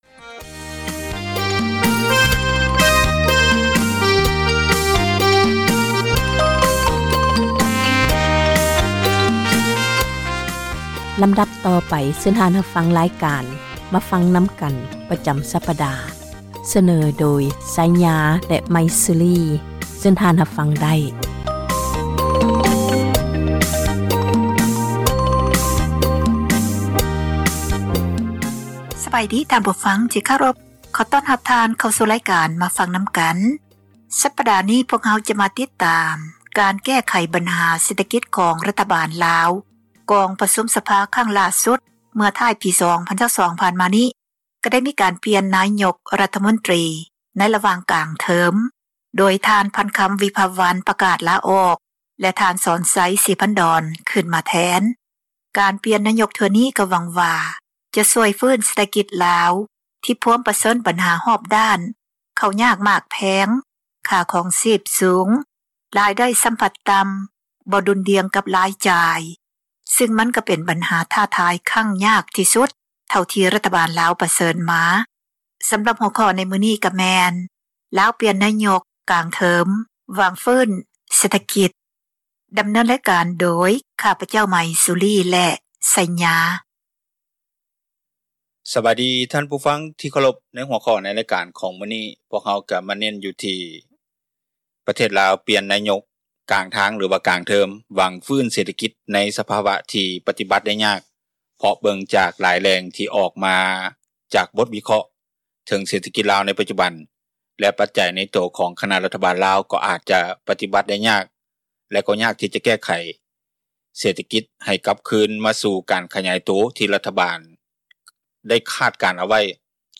ລາວ ປ່ຽນນາຍົກ ກາງເທີມ, ຫວັງຟື້ນຟູ ເສຖກິຈ – ຂ່າວລາວ ວິທຍຸເອເຊັຽເສຣີ ພາສາລາວ